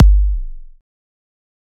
EDM Kick 16.wav